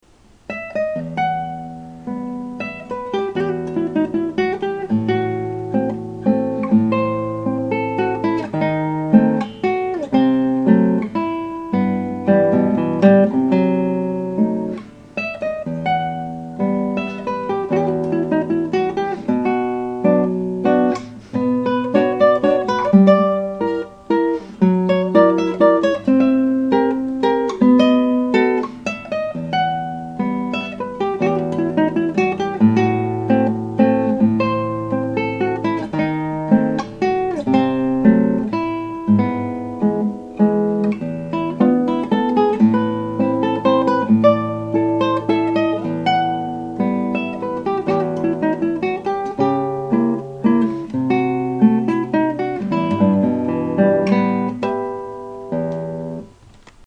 Atlanta Guitarist for Hire
A very sad/emotional work